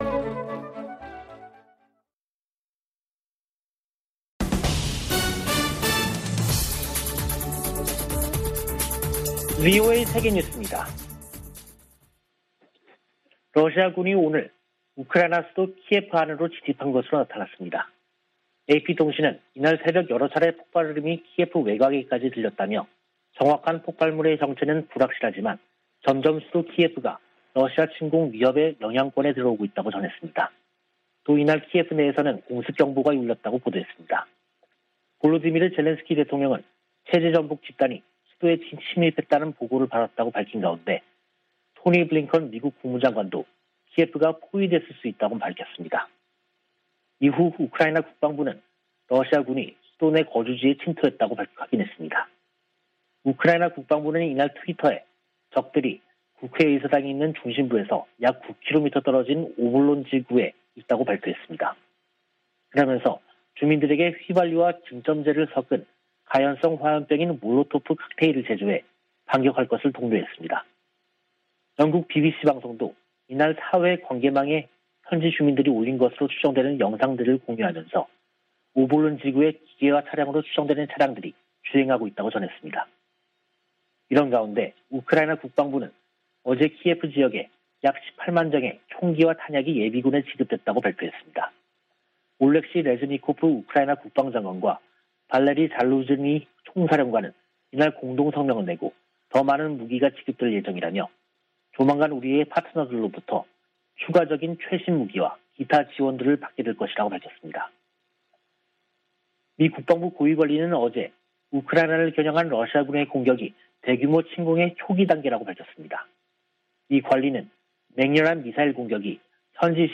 VOA 한국어 간판 뉴스 프로그램 '뉴스 투데이', 2022년 2월 25일 3부 방송입니다. 미 공화당 의원들은 러시아의 우크라이나 무력 침공에 대해 북한 등에 미칠 악영향을 우려하며 강력한 대응을 촉구했습니다. 우크라이나 침공으로 조 바이든 행정부에서 북한 문제가 뒤로 더 밀리게 됐다고 미국 전문가들은 진단했습니다. 유엔은 인도주의 기구들의 대북 송금이 막힌 문제를 해결하기 위해 특별 해법을 논의하고 있다고 밝혔습니다.